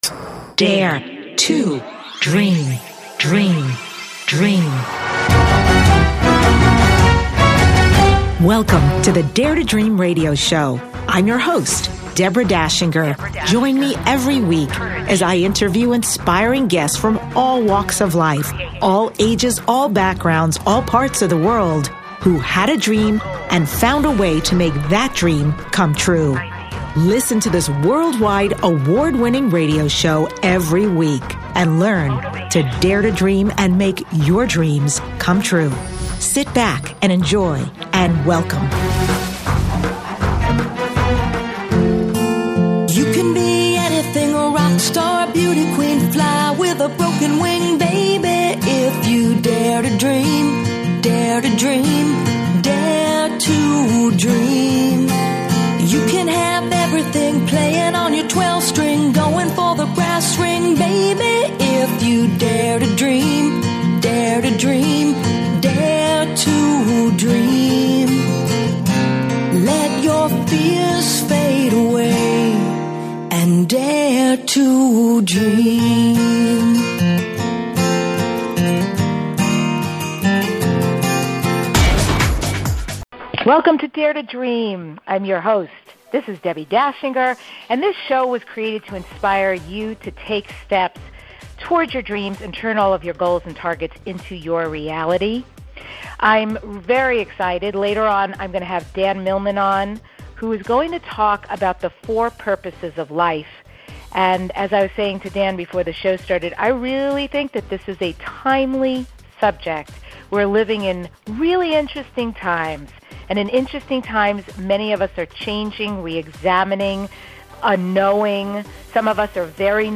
Talk Show Episode, Audio Podcast, Dare To Dream and Guest, Dan Millman on , show guests , about Dare To Dream, categorized as Health & Lifestyle,Kids & Family,Psychology,Personal Development,Self Help,Motivational,Spiritual